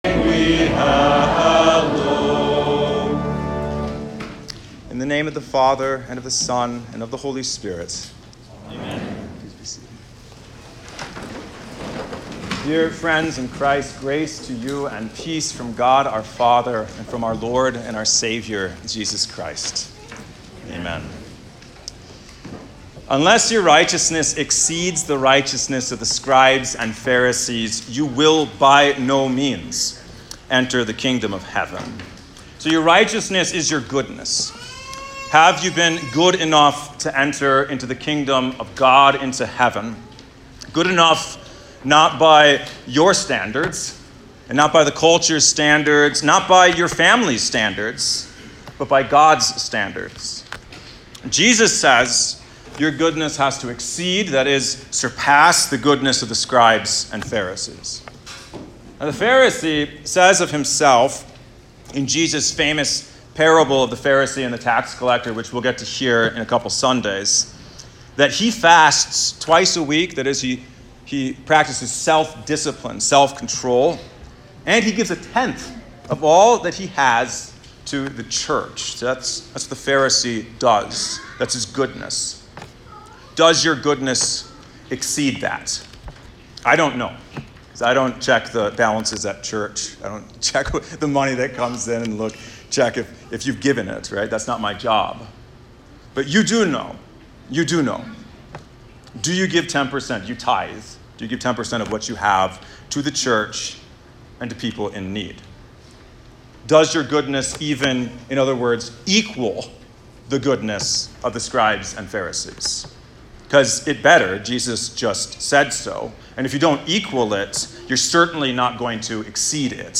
Bible Text: Matthew 5:17-26 | Preacher